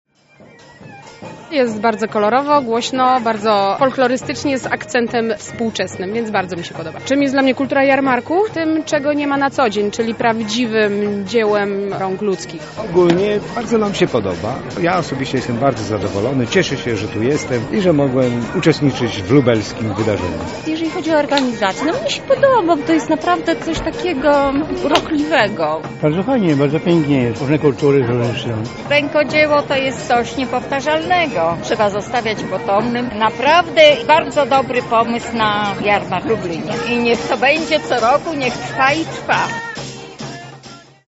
Uczestnicy podzielili się z nami opinią na temat festiwalu.